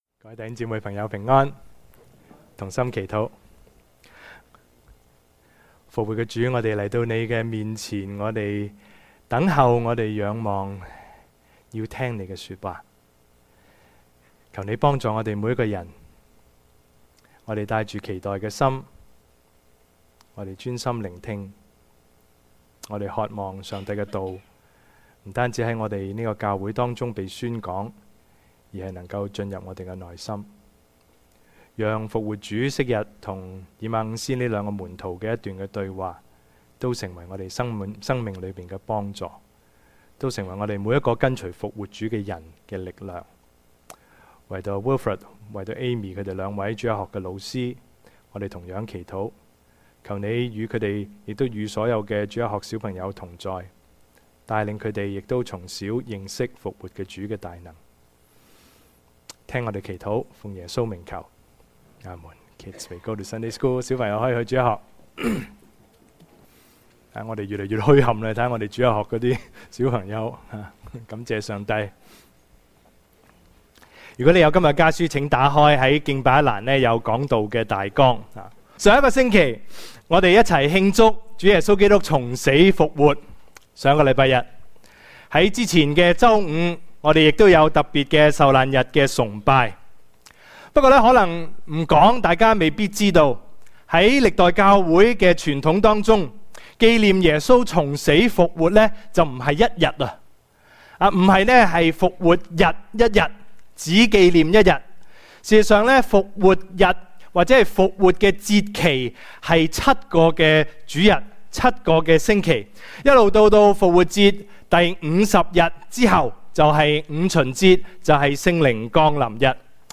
Sermon / 講道錄音 – Page 66 – Toronto Emmanuel Church